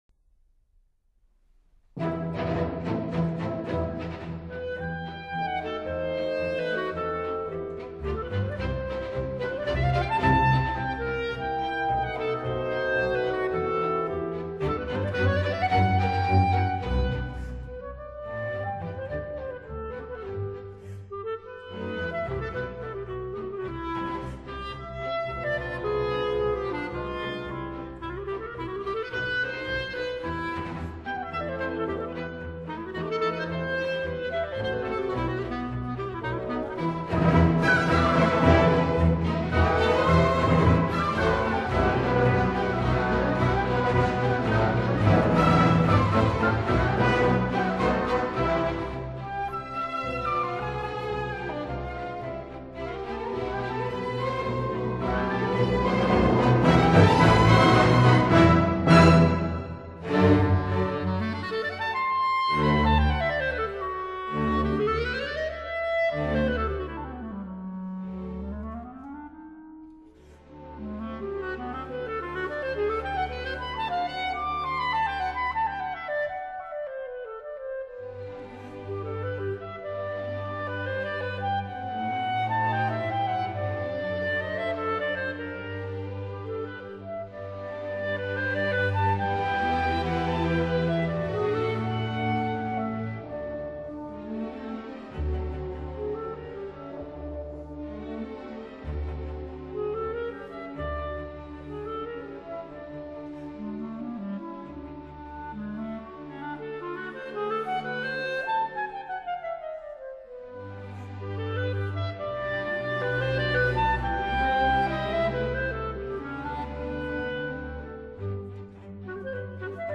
Konzert für Klarinette und Orchester Nr.4